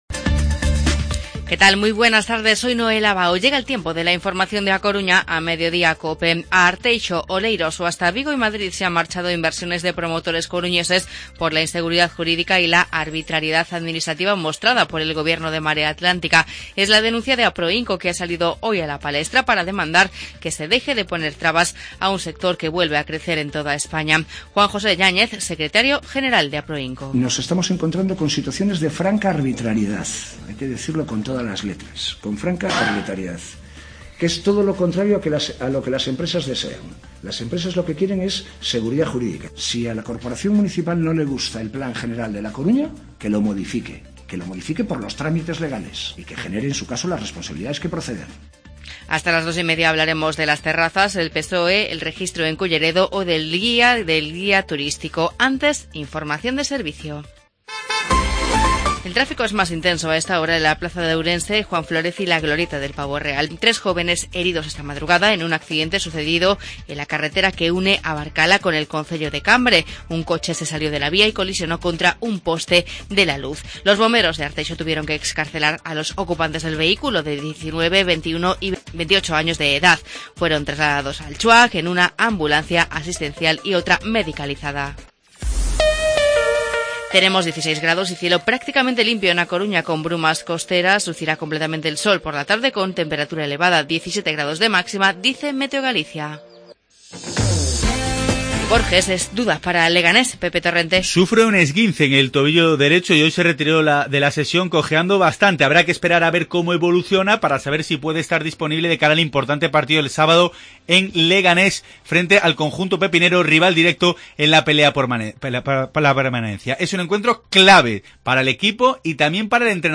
Informativo Mediodía COPE Coruña martes, 21 de febrero de 2017